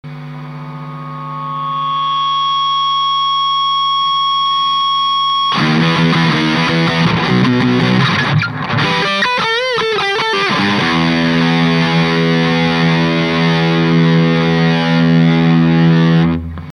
Guitar MoonStoratoShape
Amplifier VOX AD30VT UK'80S
他２名と比較して段違いに好みです。
ノイズも一番少ない気がしますし。